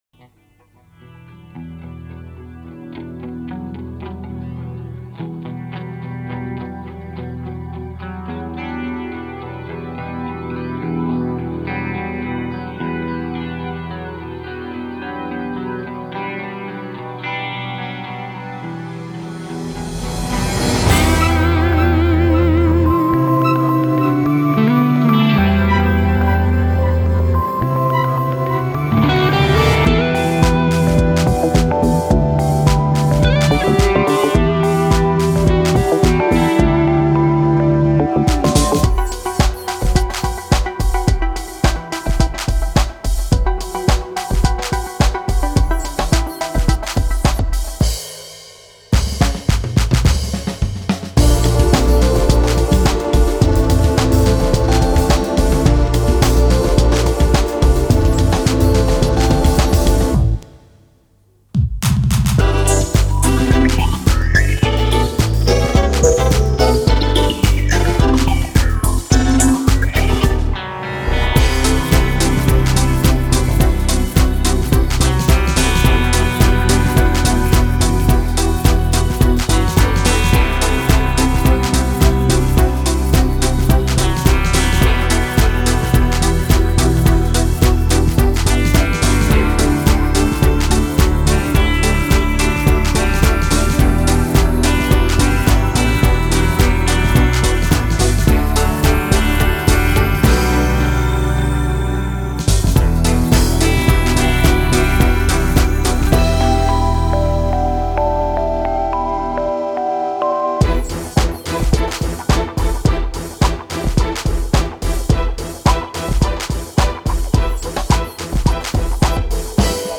fröhlich, tanzbar
Tempo 107 4/4 Em